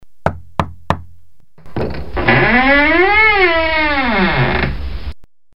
Ну ты же не каждый вечер это обсуждаешь перед сном) Вложения stuk-i-strashniy-skrip-dveri.mp3 stuk-i-strashniy-skrip-dveri.mp3 217,3 KB